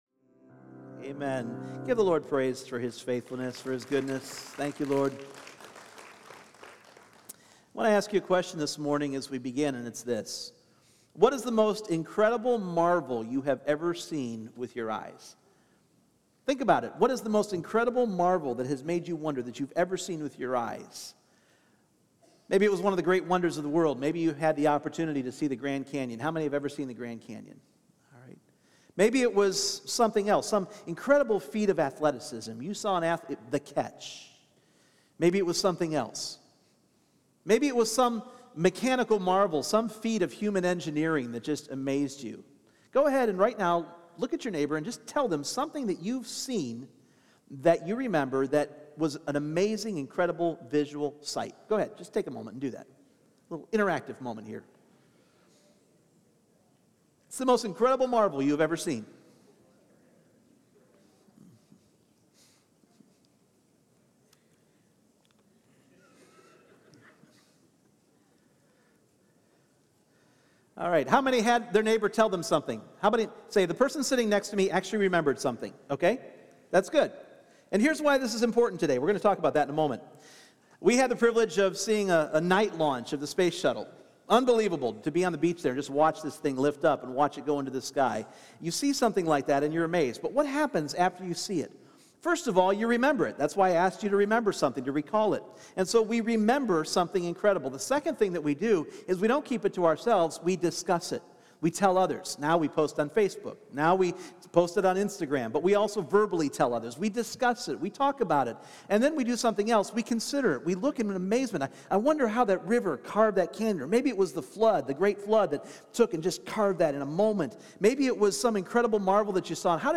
Individual Messages Service Type: Sunday Morning Imagine the most incredible sight you have ever seen!